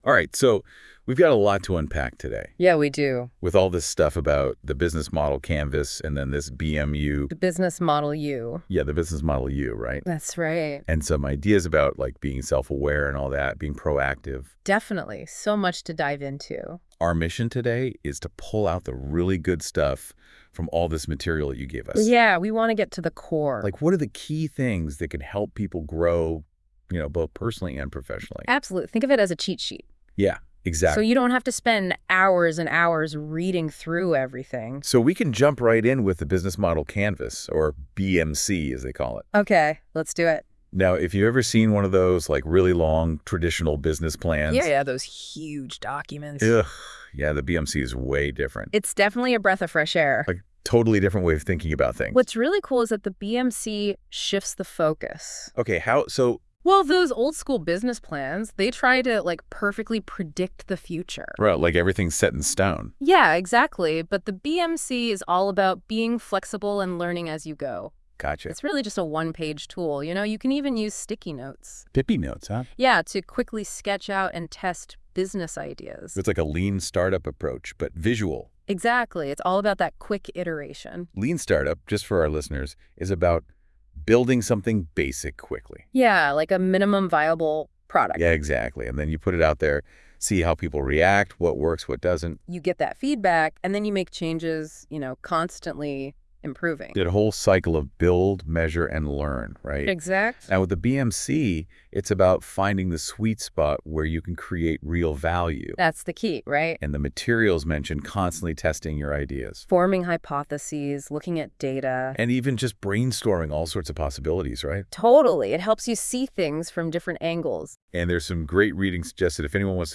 Episode 7 - AI Talk Show Week 5 - TorontoMet Entrepreneur Institute, Turning Ideas Into Reality - Located in Toronto, ON